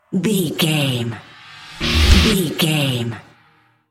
Aeolian/Minor
D
drums
electric guitar
bass guitar
hard rock
lead guitar
aggressive
energetic
intense
nu metal
alternative metal